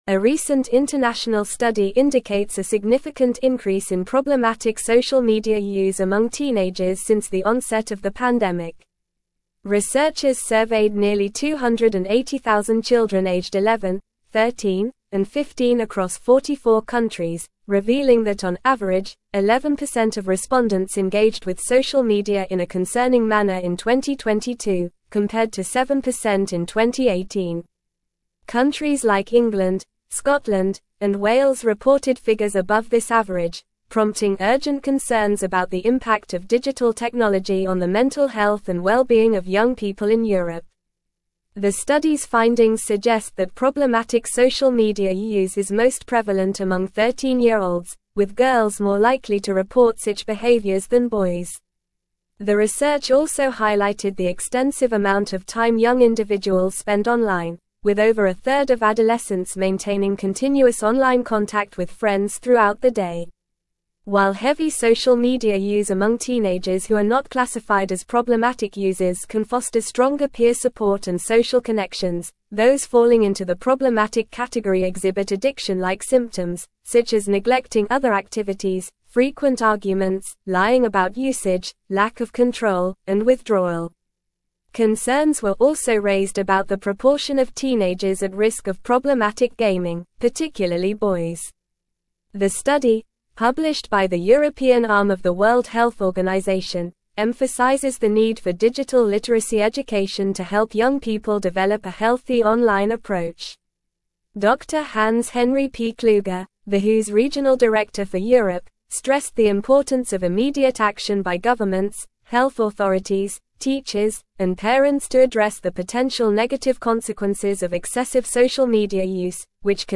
Normal
English-Newsroom-Advanced-NORMAL-Reading-Concerning-Increase-in-Teenage-Social-Media-Use-Detected.mp3